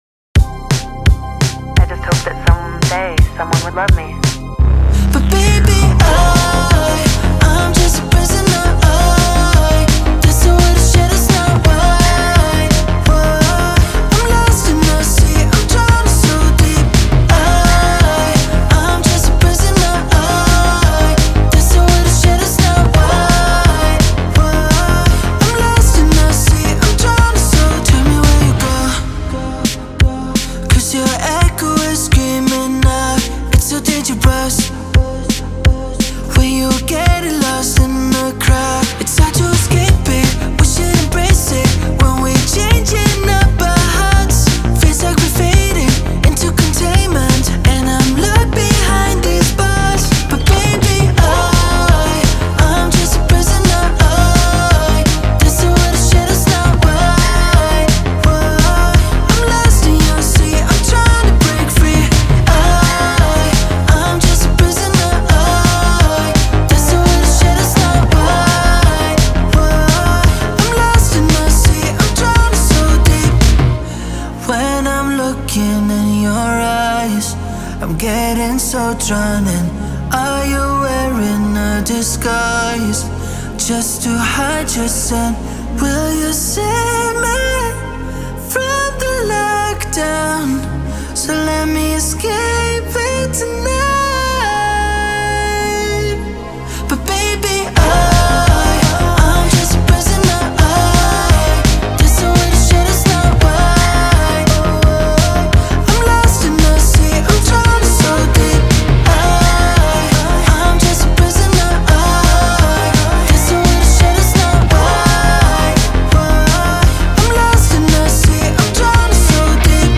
una canción synth pop optimista.